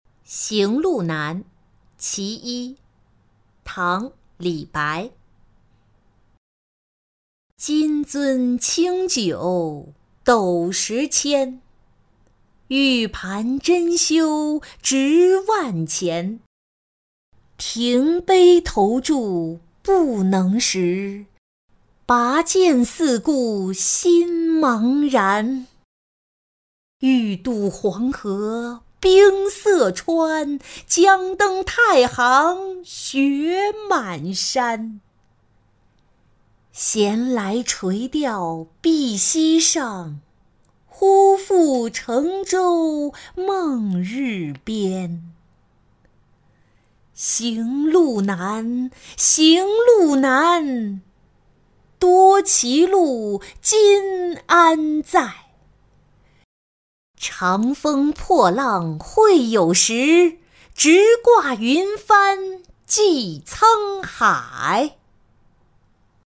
小石城山记-音频朗读